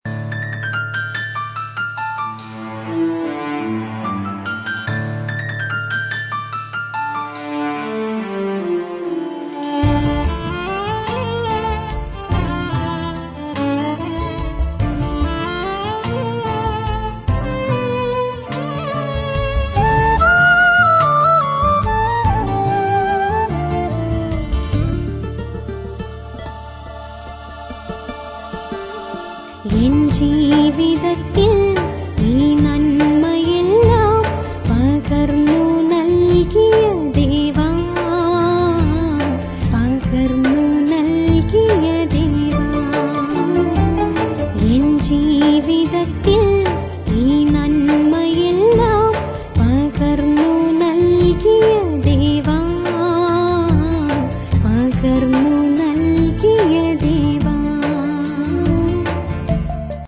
Malayalam Christian Devotional Songs